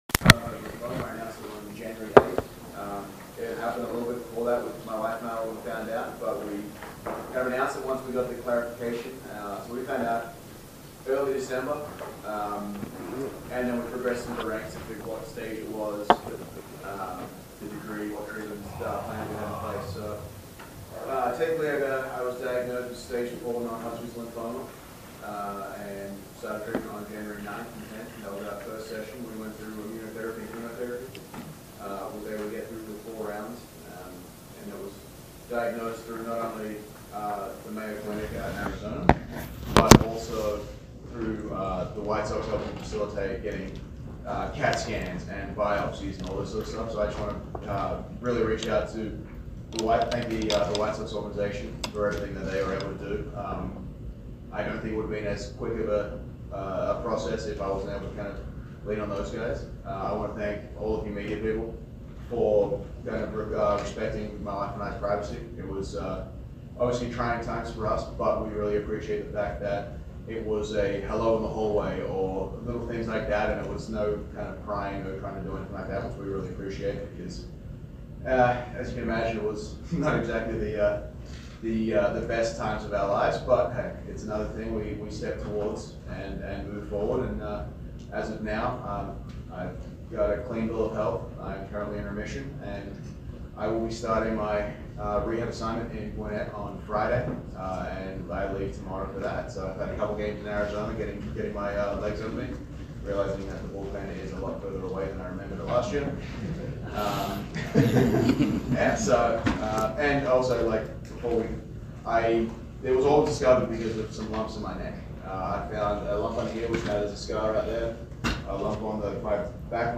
Liam Hendriks is talking about beating cancer and rejoining the White Sox taking you behind the scene unedited and uninterrupted.